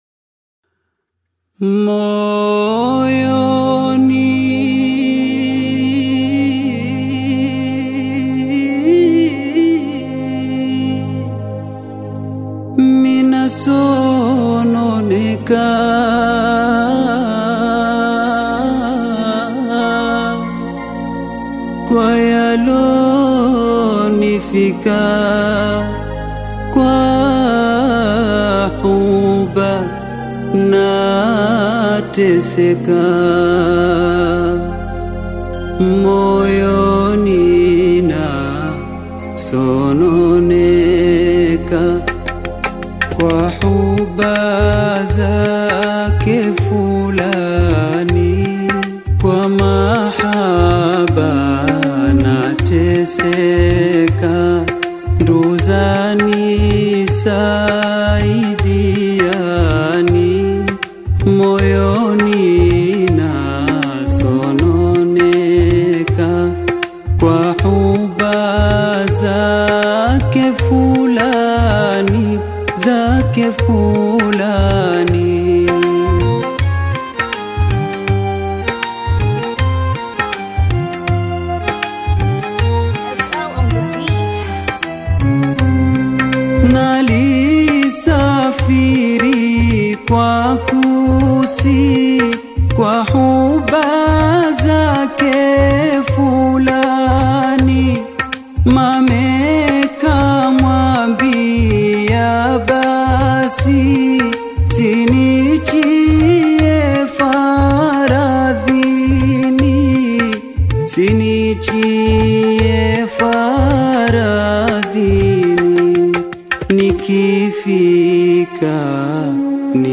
Tanzanian bongo flava artist singer and songwriter
African Music